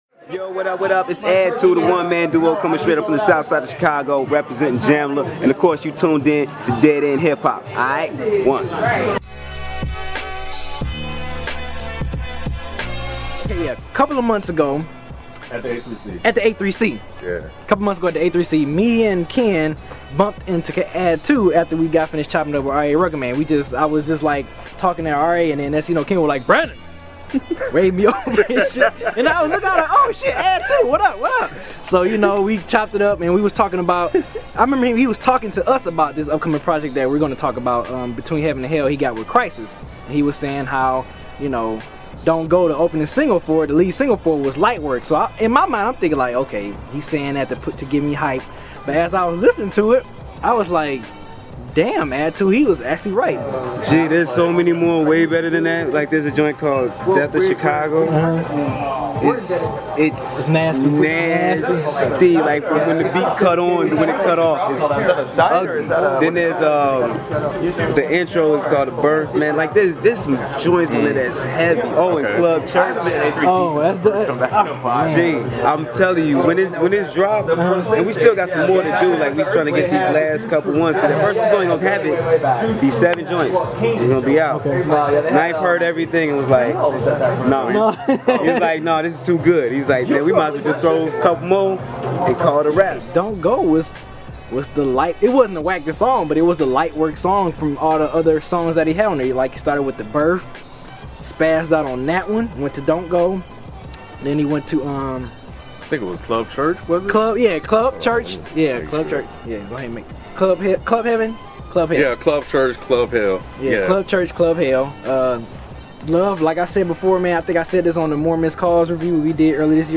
Between Heaven & Hell Review\Interview